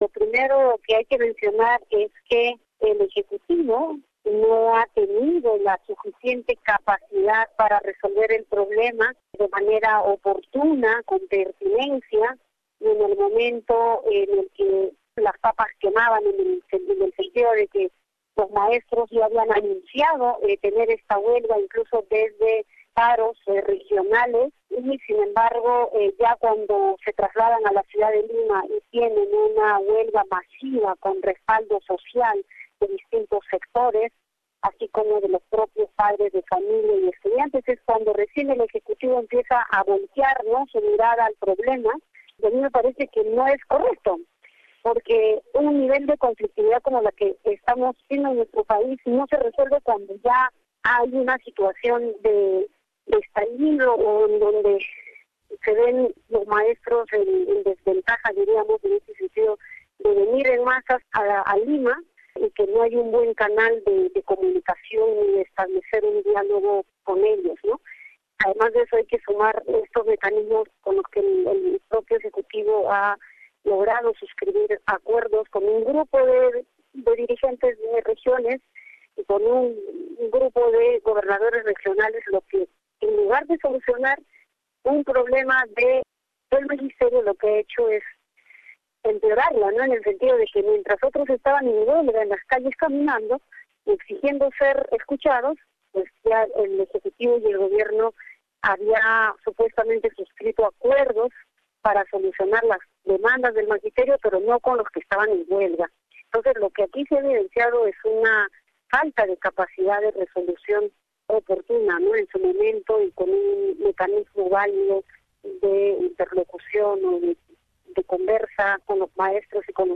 Entrevista con la congresista Tania Pariona, quien trabaja en la mesa de negociación del gobierno con los educadores.